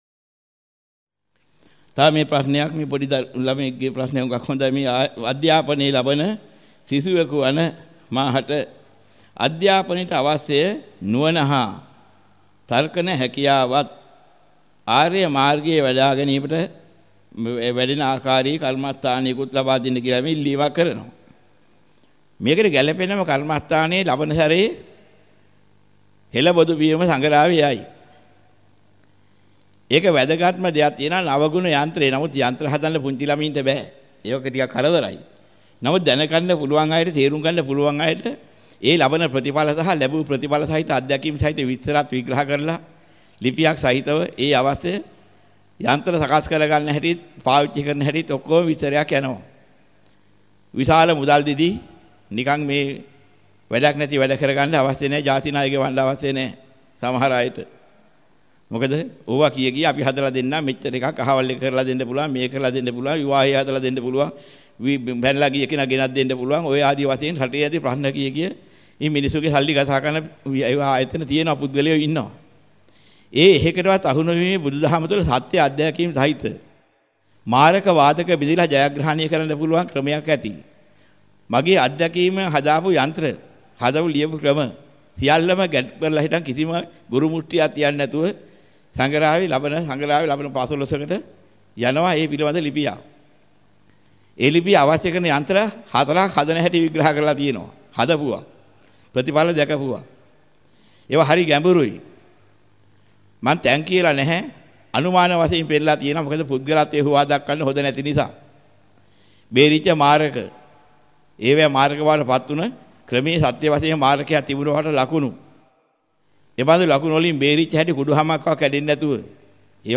වෙනත් බ්‍රව්සරයක් භාවිතා කරන්නැයි යෝජනා කර සිටිමු 22:40 10 fast_rewind 10 fast_forward share බෙදාගන්න මෙම දේශනය පසුව සවන් දීමට අවැසි නම් මෙතැනින් බාගත කරන්න  (14 MB)